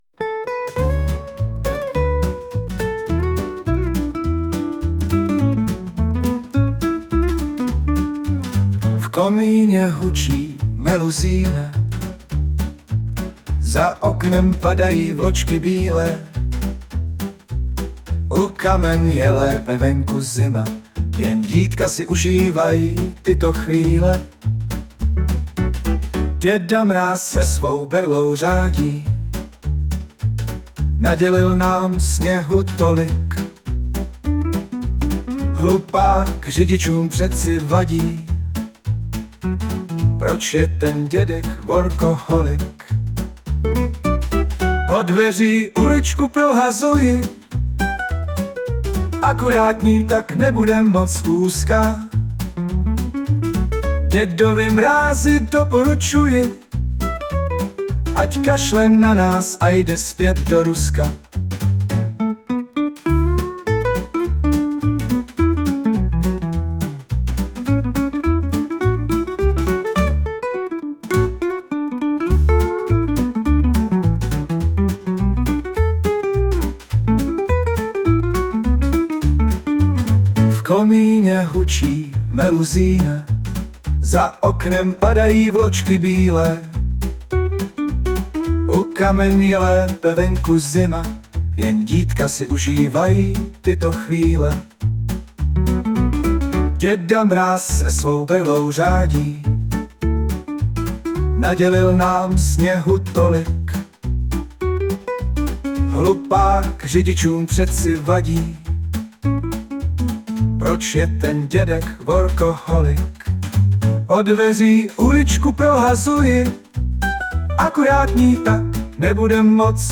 Básně » Humor
* hudba, zpěv: AI